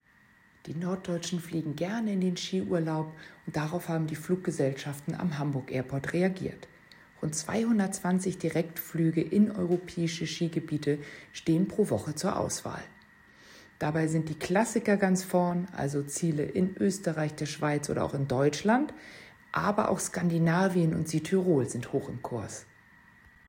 O-Ton (Audio)